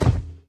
walk2.ogg